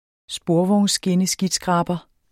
Udtale [ ˈsboɐ̯vɒwnsgenəˌsgidˌsgʁɑːbʌ ]